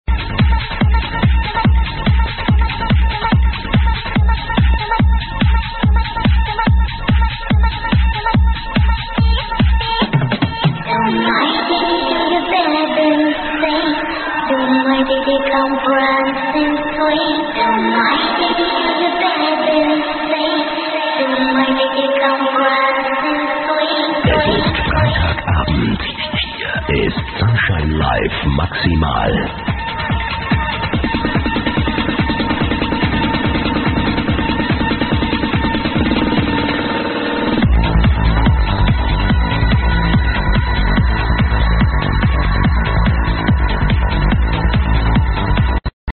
in Frankfurt,Germany